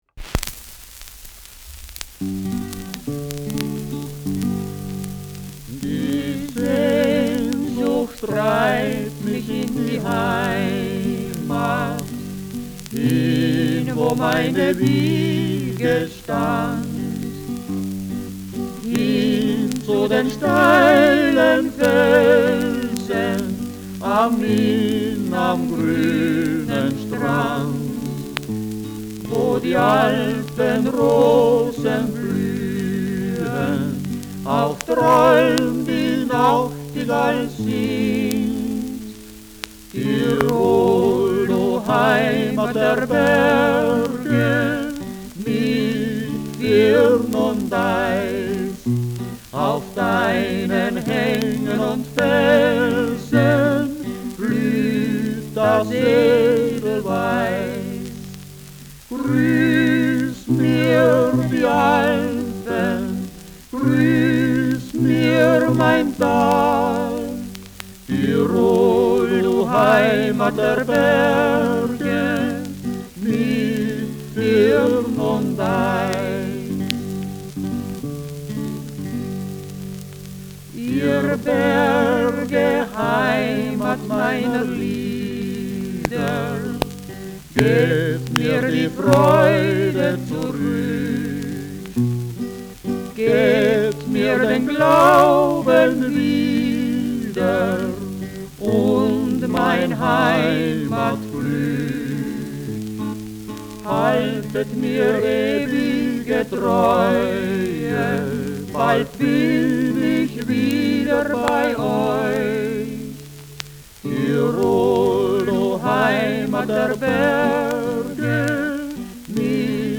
Schellackplatte
Gelegentlich stärkeres Knacken
[Berlin] (Aufnahmeort)
Folkloristisches Ensemble* FVS-00015